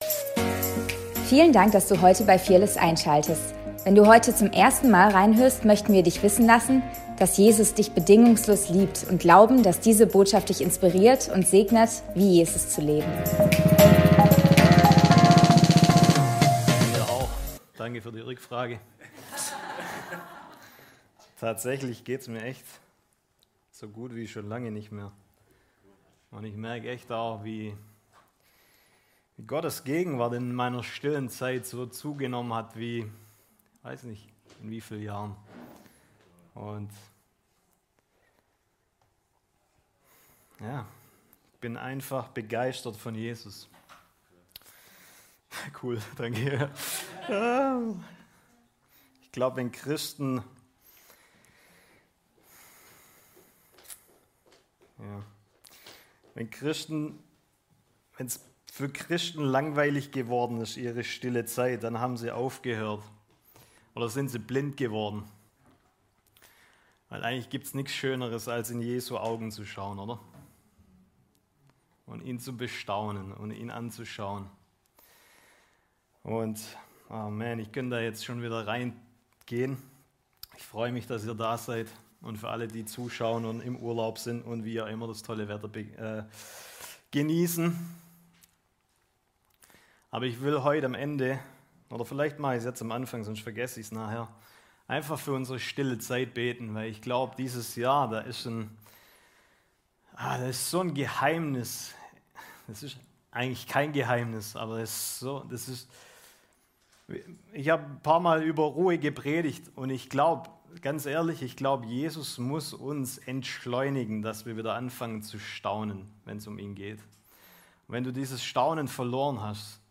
Predigt vom 09.03.2025